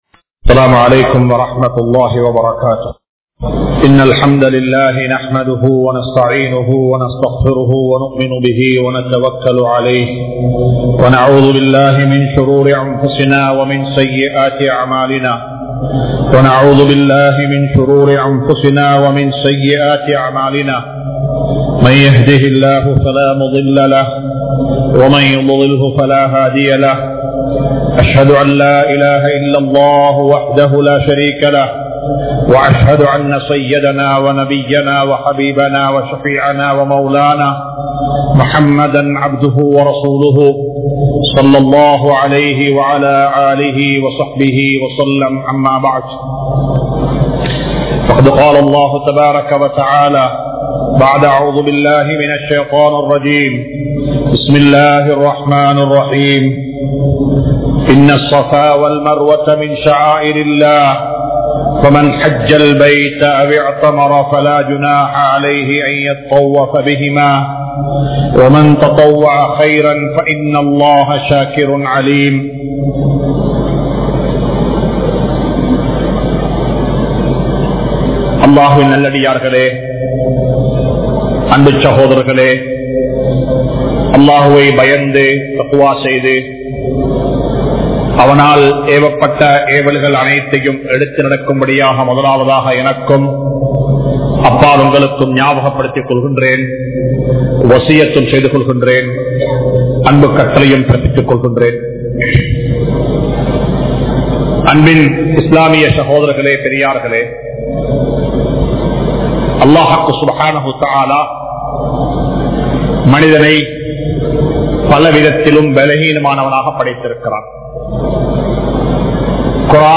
Muslim Samoohathai Vitkaatheerhal(முஸ்லிம் சமூகத்தை விற்காதீர்கள்) | Audio Bayans | All Ceylon Muslim Youth Community | Addalaichenai
Majma Ul Khairah Jumua Masjith (Nimal Road)